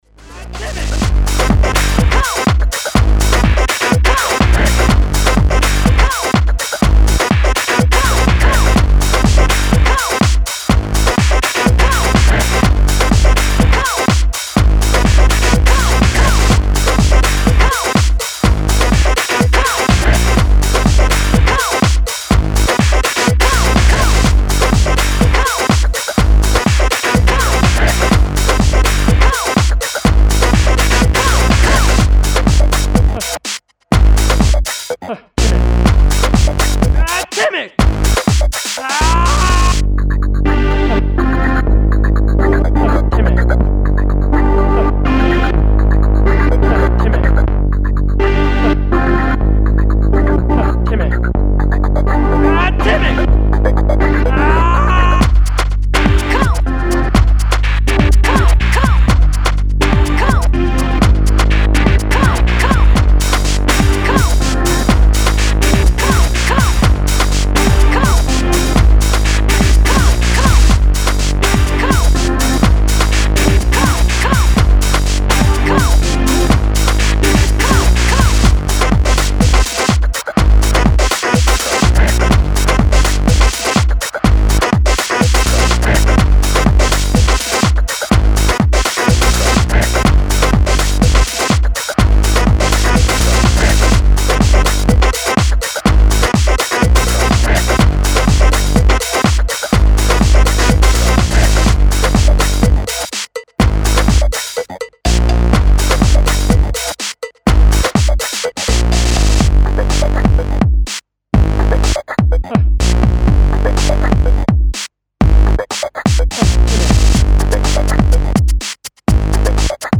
Style: Brighton Techno / Electro